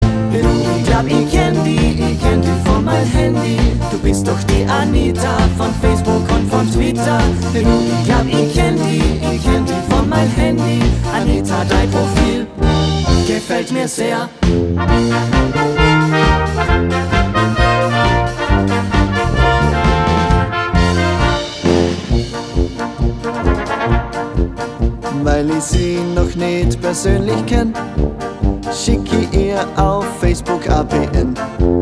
Gattung: für Kleine Blasmusik
Besetzung: Kleine Blasmusik-Besetzung
1. Alt-Saxophon in Es
1. Tenor-Saxophon in B
Bariton-Saxophon in Es
1. Trompete / 1. Flügelhorn in B
1. Posaune in B/C
E-Bass
Gitarre
Keyboard (Gesang/Klavier)
Schlagzeug